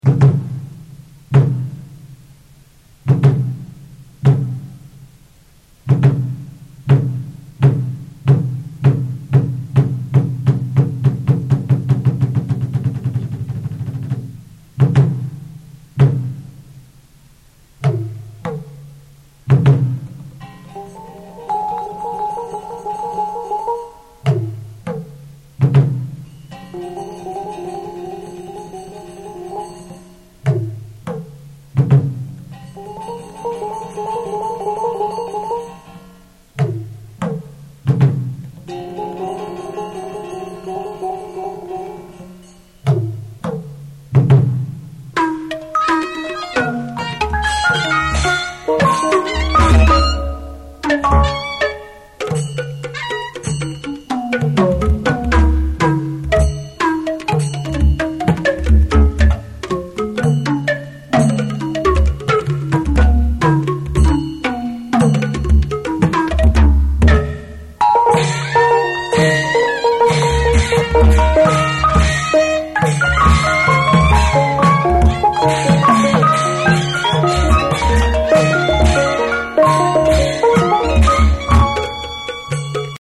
The mysterious and flamboyant Burmese drum and gong ensemble, rarely heard outside Burma (and is increasingly rare within Burma as well).
Although these instruments are somewhat reminiscent of some traditional Thai or Indonesian gong ensembles, this seems almost reckless, sometimes on the verge of falling apart, but suddenly coming together, only to fall into chaos yet again.
Note:  This music is from a cassette we purchased after watching the marionette show.
As it's tied to the action onstage, the music never stays at the same tempo for long. Occasionally, a reverby vocal will enter the mix.